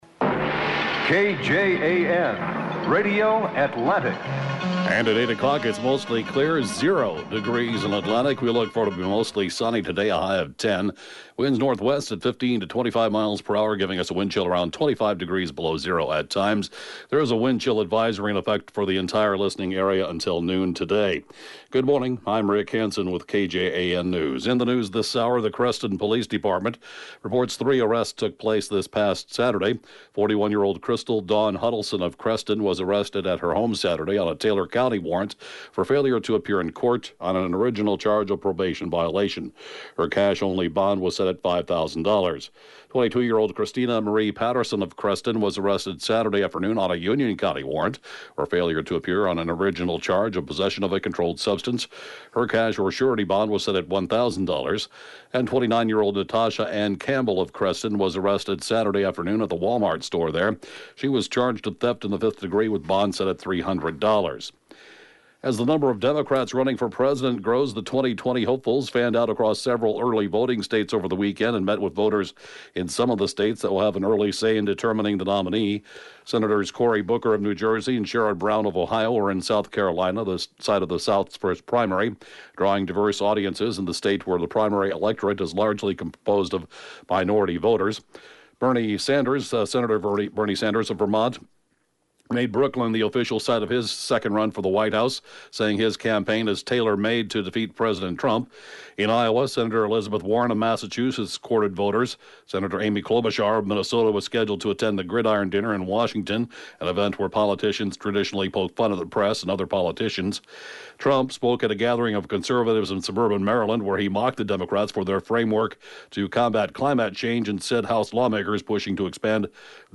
(Podcast) KJAN 8-a.m. News, 3/27/19